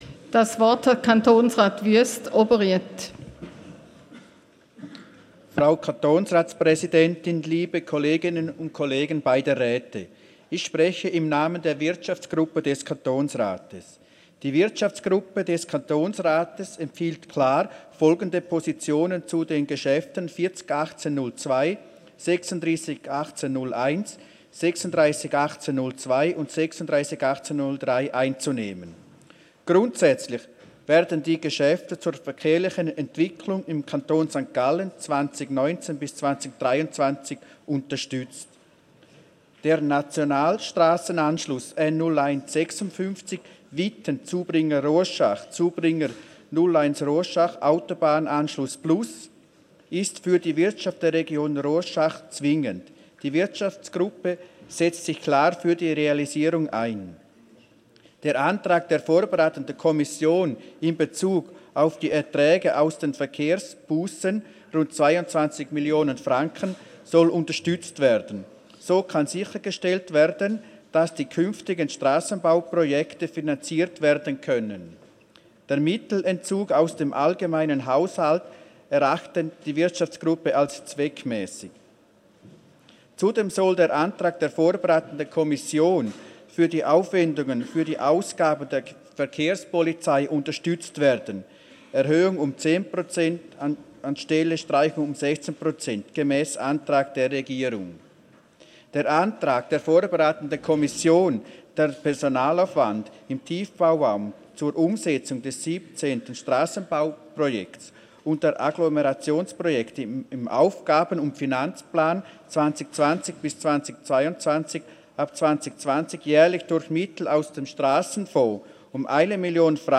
18.9.2018Wortmeldung
Session des Kantonsrates vom 17. bis 19. September 2018